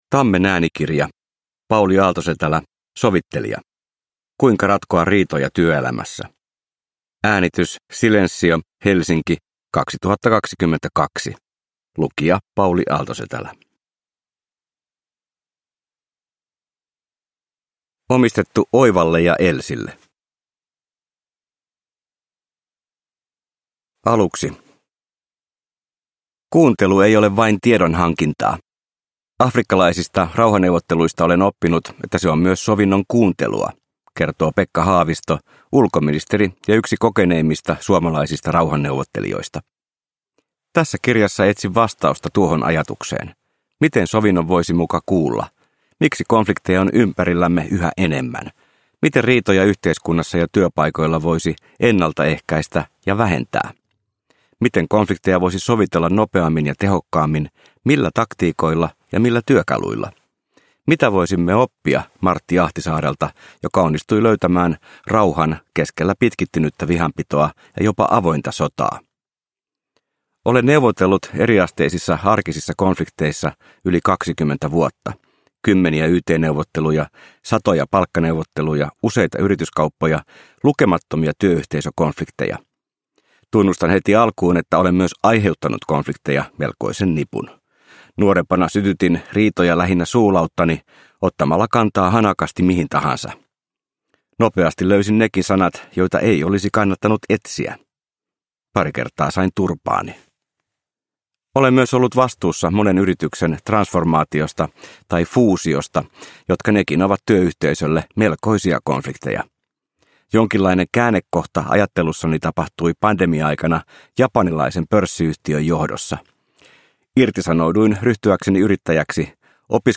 Sovittelija – Ljudbok – Laddas ner